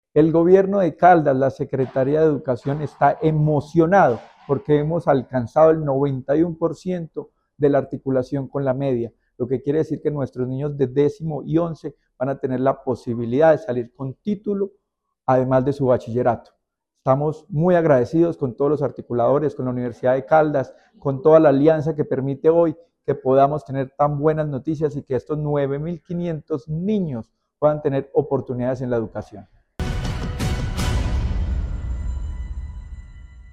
Luis Herney Vargas Barrera, secretario de Educación de Caldas.